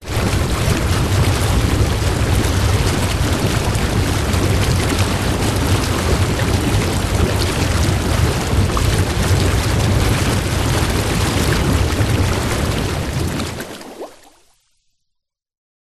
Звуки спа, атмосфера
Спа, джакузи работает рядом, пузыри в воде, потом выключается